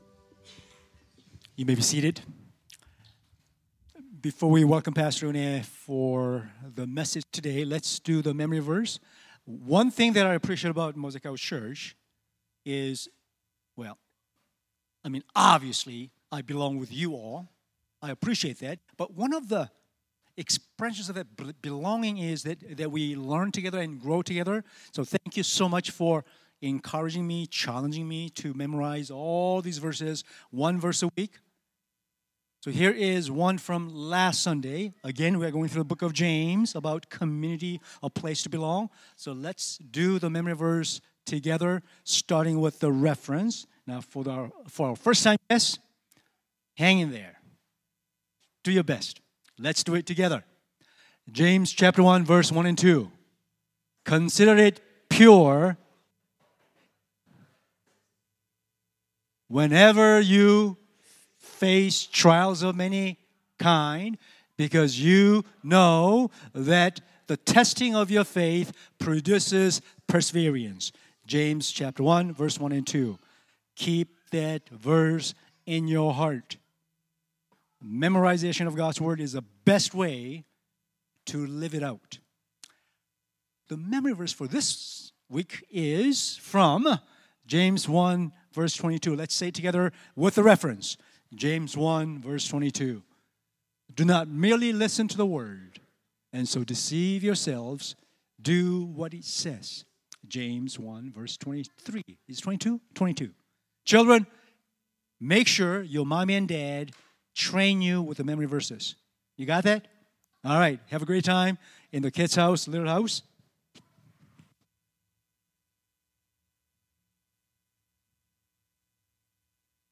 Sermons | mosaicHouse
Guest Speaker